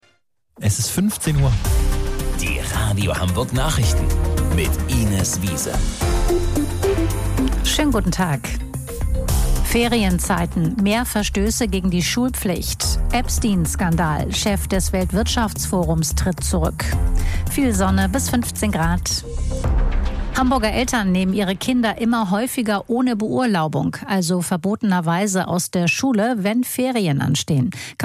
Radio Hamburg Nachrichten vom 26.02.2026 um 15 Uhr